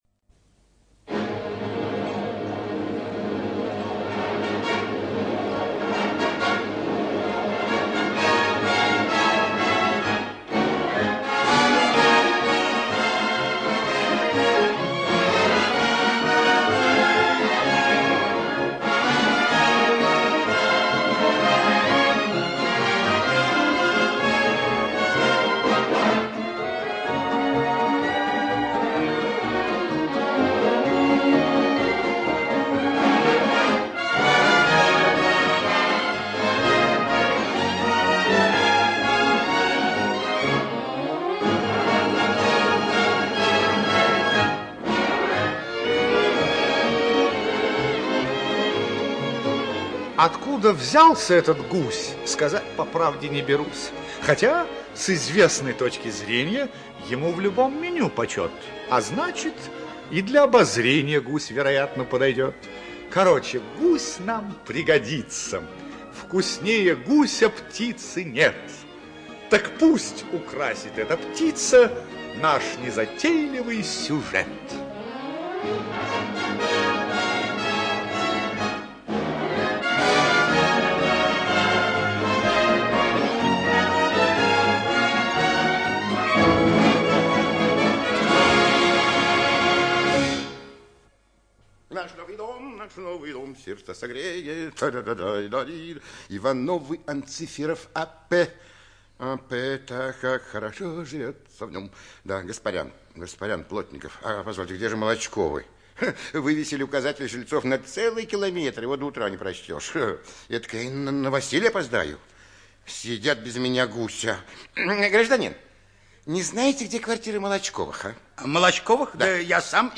ЧитаютПлятт Р., Грибов А., Жаров М., Симонов Р., Доронин В., Вовси А.
ЖанрРадиоспектакли